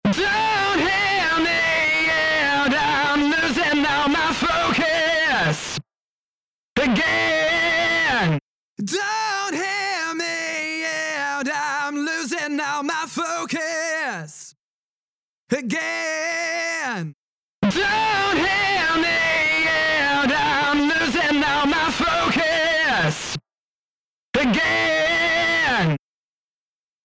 パワフルなディストーション＆サチュレーション・エンジン
CrushStation | Vocals | Preset: Early 2000s Vocal Solo
CrushStation-Eventide-Vocals-Early-2000s-Vocal-Solo.mp3